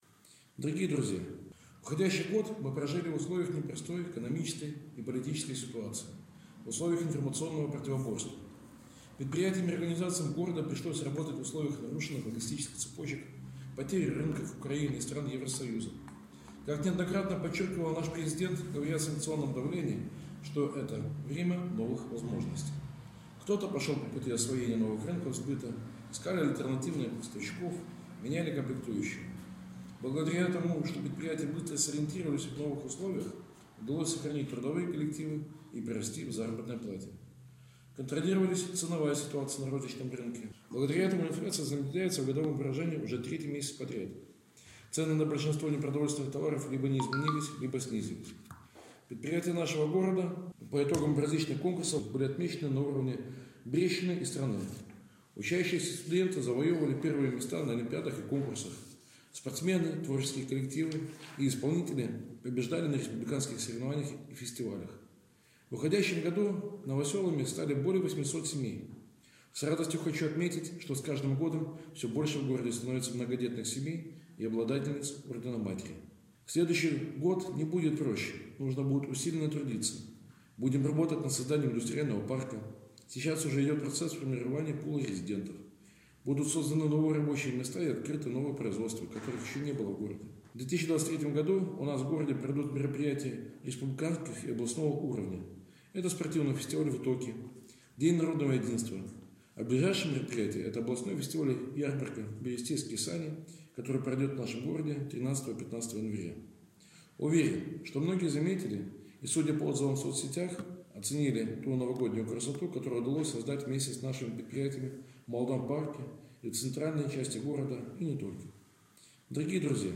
Новогоднее поздравление председателя Барановичского горисполкома
Поздравление с Новым годом председателя Барановичского городского исполнительного комитета Михаила Баценко